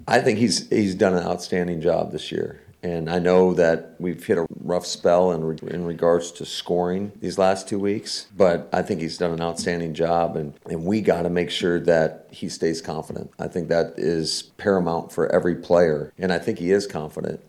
(LEARFIELD) – Green Bay Packers coach Matt LaFleur covered a lot of topics when he spoke with the media on Thursday.